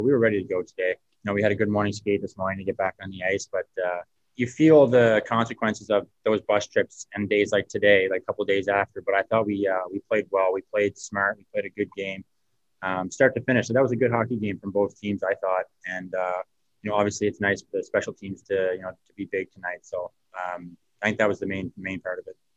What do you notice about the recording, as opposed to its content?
Speaking to media post game